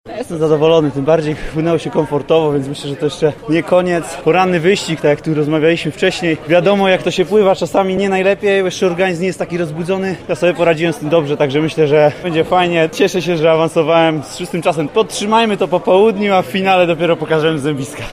Mówi Kacper Majchrzak.